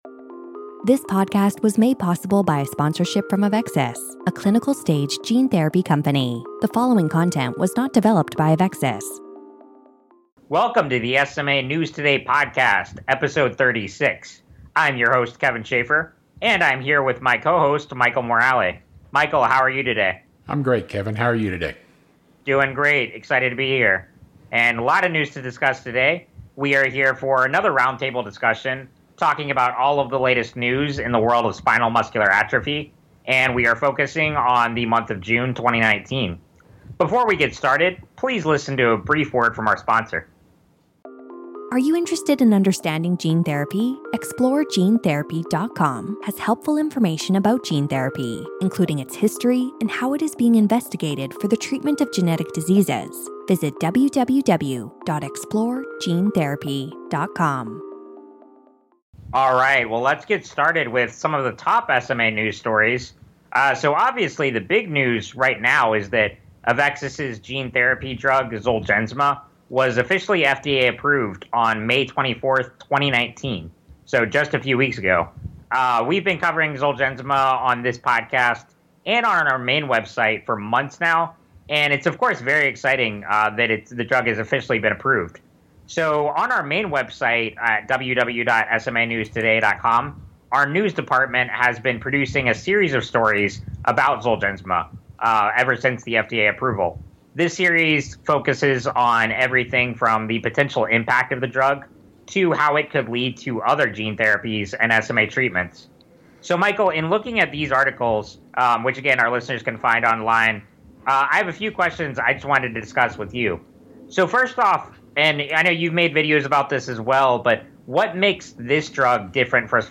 #36 - Roundtable Discussion - Discussion for June 2019